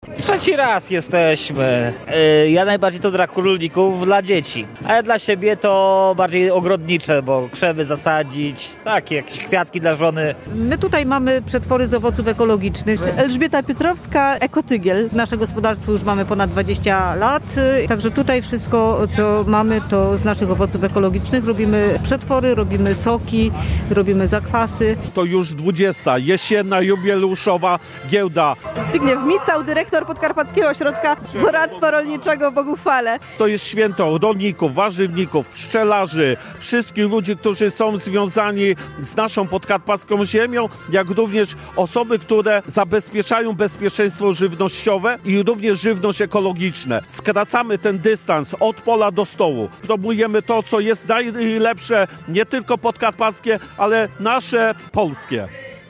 Relacja
Obrazek-Jesienna-Gielda-Ogrodnicza.mp3